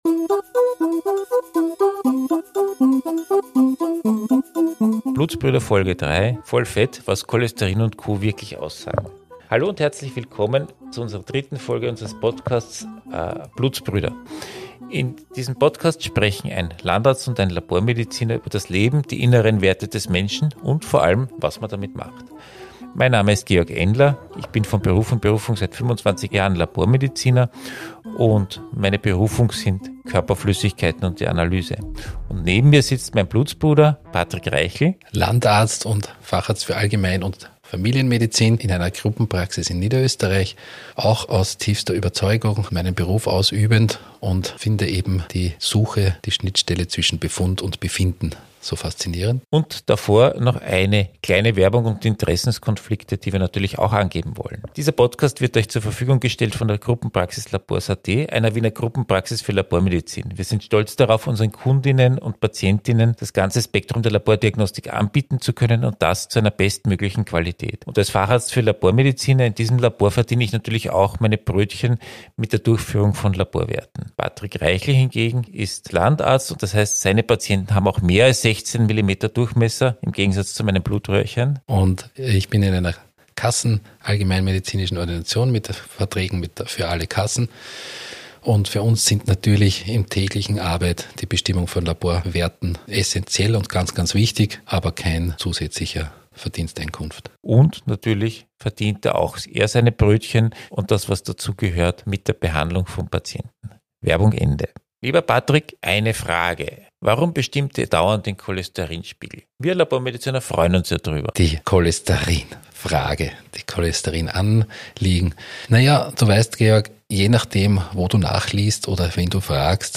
Folge 3 Voll fett - Was Cholesterin und Co wirklich aussagen ~ Blutsbrüder - Ein Landarzt und ein Labormediziner sprechen über die inneren Werte Podcast